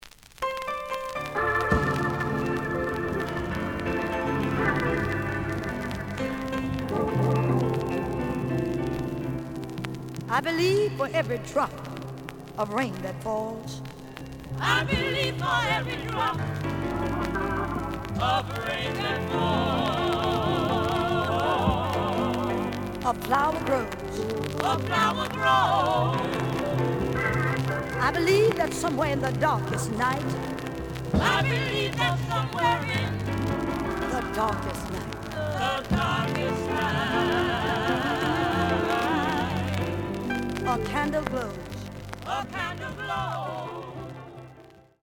The audio sample is recorded from the actual item.
●Format: 7 inch
●Genre: Gospel